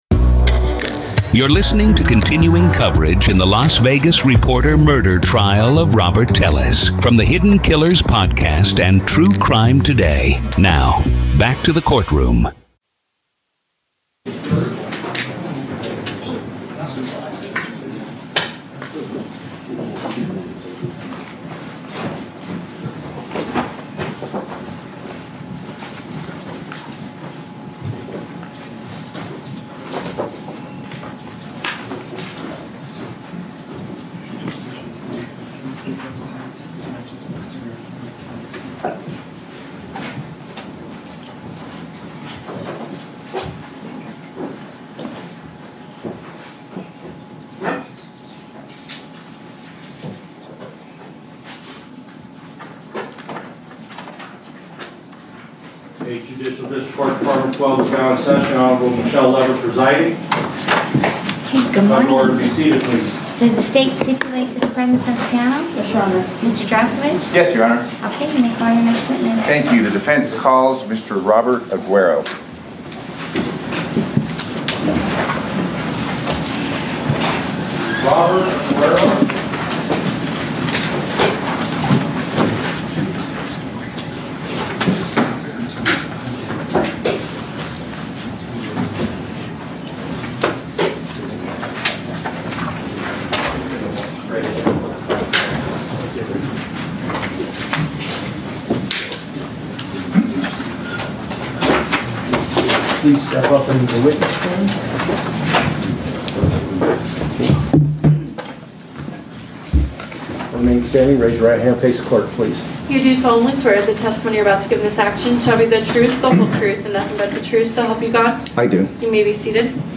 Telles Takes the Stand-Raw Court Audio-NEVADA v. Robert Telles DAY 6 Part 1